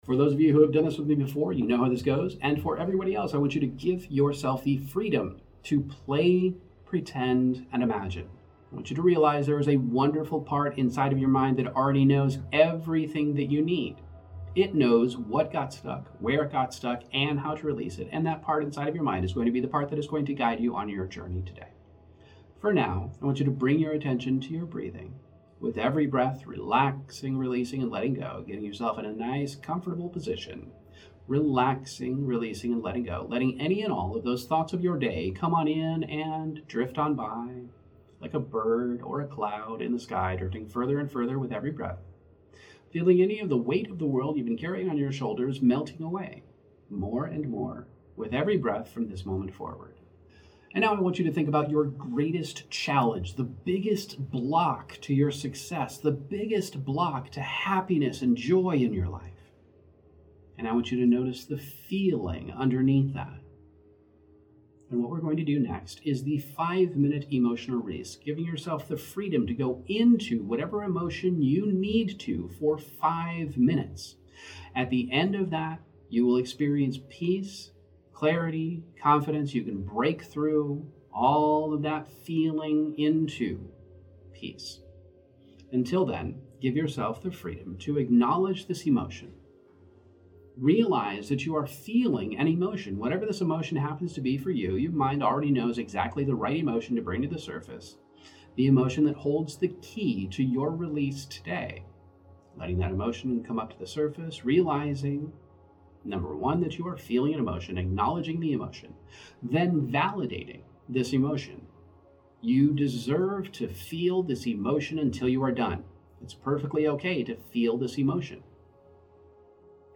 Emotional Optimization™ Meditations